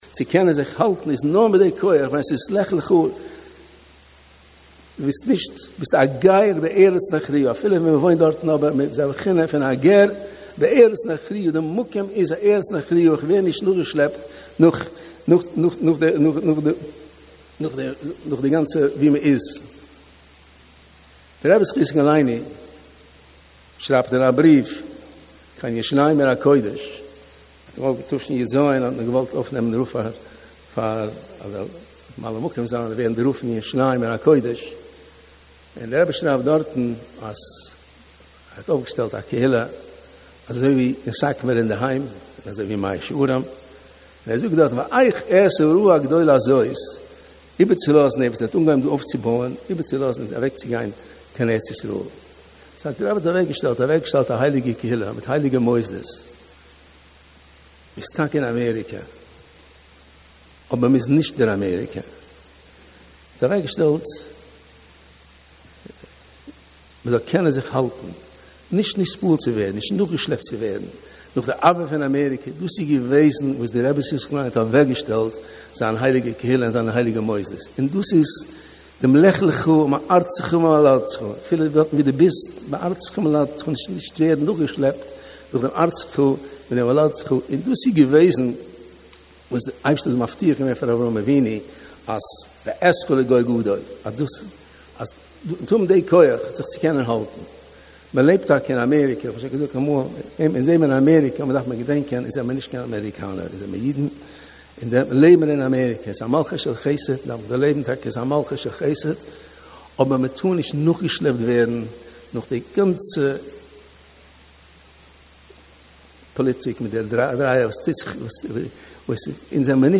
דברות קודש בענינים העומדים על הפרק מאת כ"ק מרן אדמו"ר מ'סאטמאר שליט"א (מהר''יי) מתוך דרשת פתיחת הזמן בהיכל ישיבה גדולה יום ג' לך לך תשפ"ה